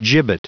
Prononciation du mot gibbet en anglais (fichier audio)
Prononciation du mot : gibbet